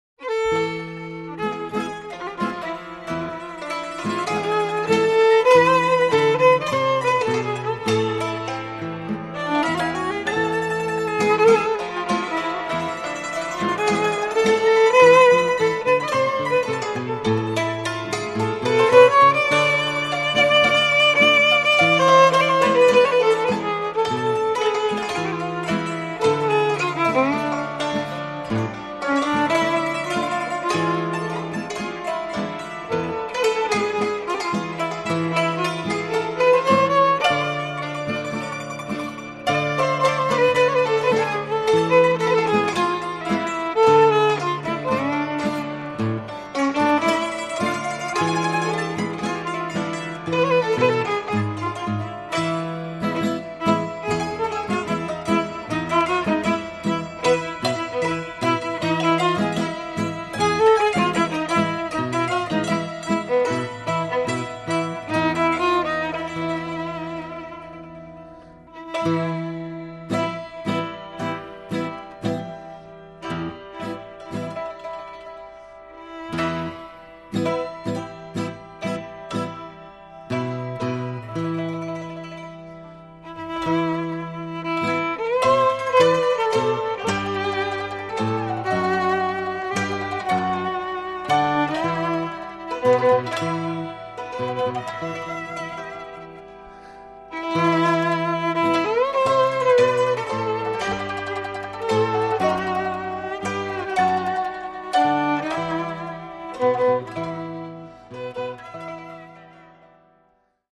οργανικό καθιστικός σκοπός η/και πατινάδα
βιολί
μαντολίνο
σαντούρι
κιθάρα Προέλευση